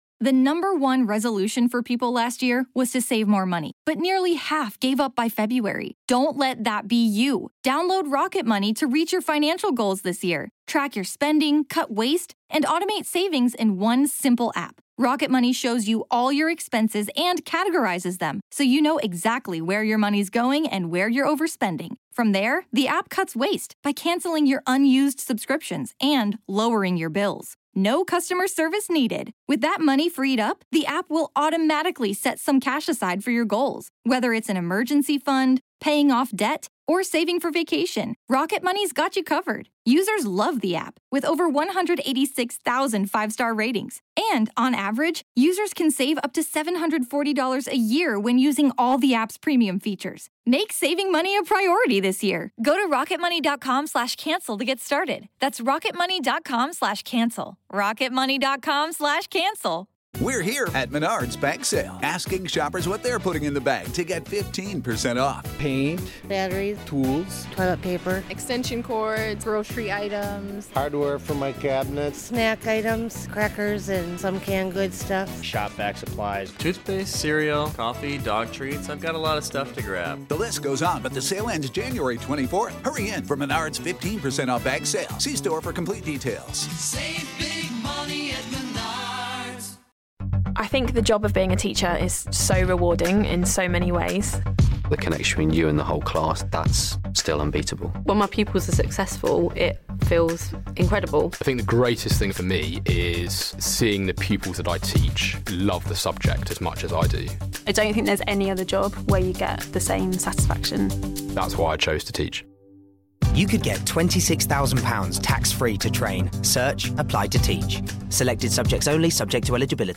E3 2015: Mirror's Edge Catalyst Interview